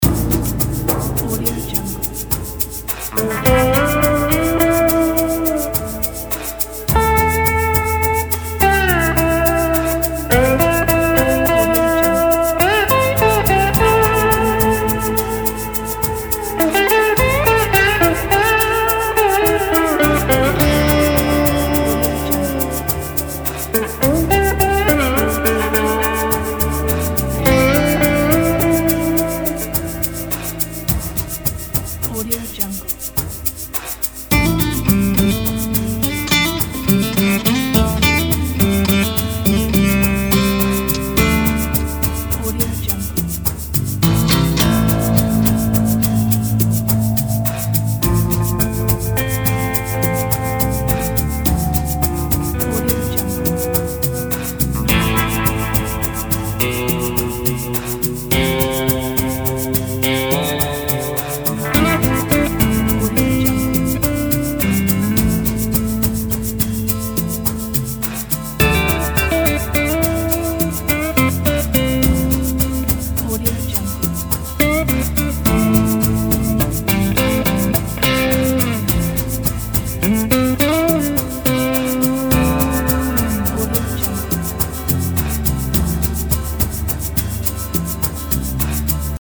کانتری، وسترن